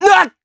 Neraxis-Vox_Damage_jp_01.wav